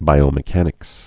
(bīō-mĭ-kănĭks)